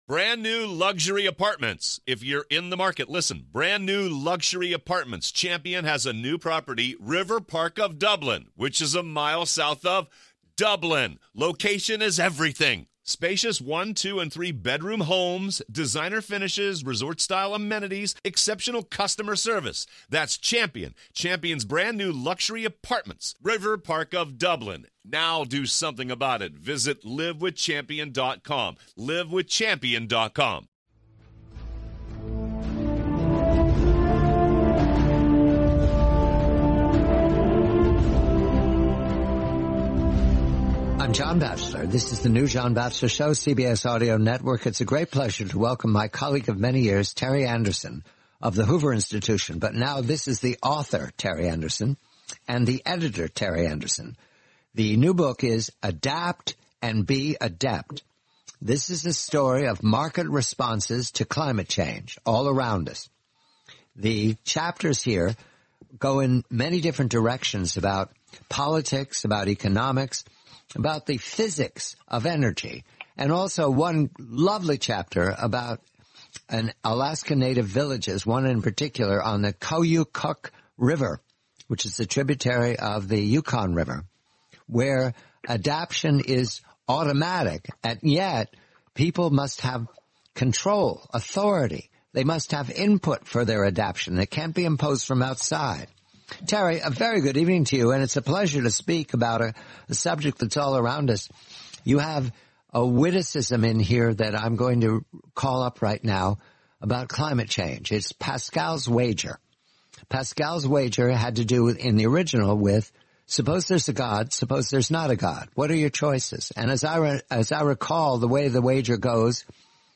The complete, forty-minute interview.